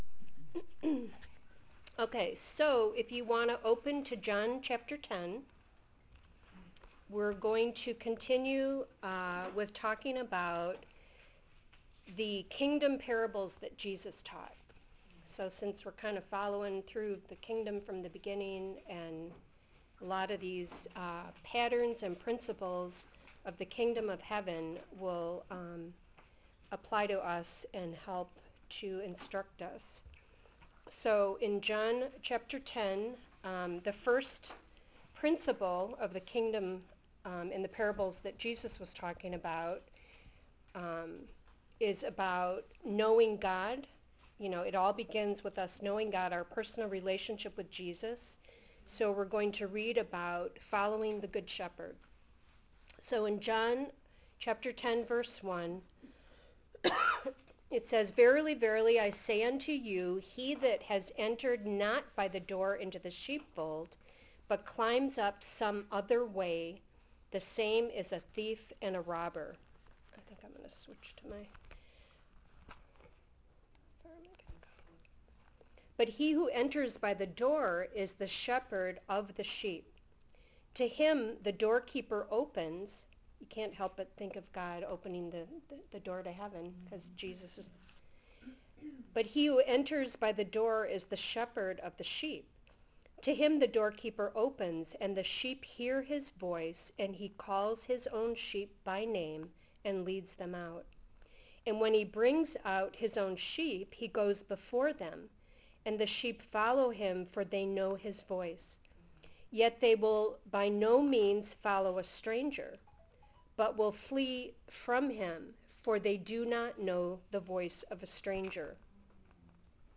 003 - Kingdom Parables (2025 Women’s Conference)